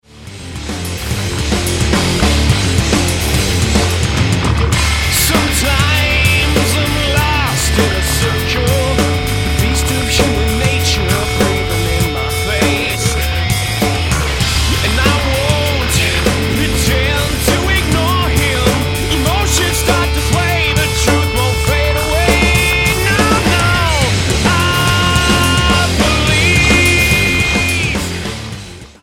9332 Style: Rock Approach